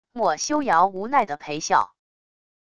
墨修尧无奈的赔笑wav音频